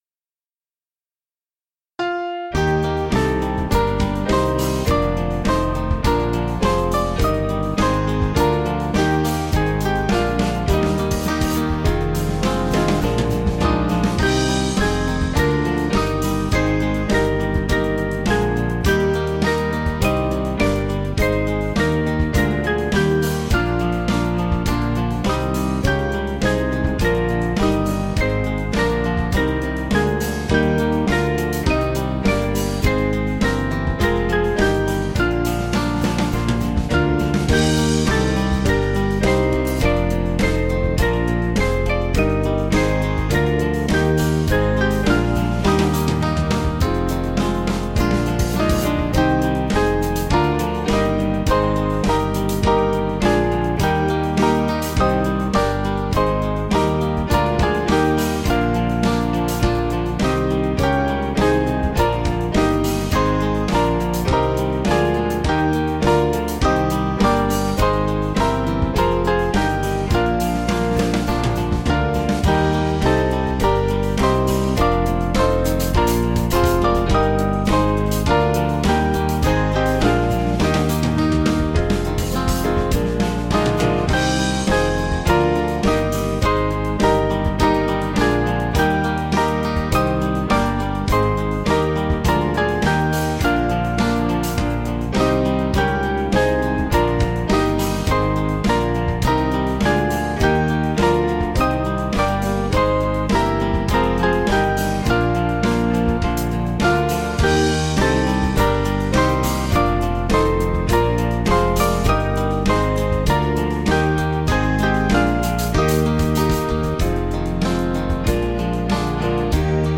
Small Band
(CM)   3/Eb 482.1kb